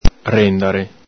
Alto Vic.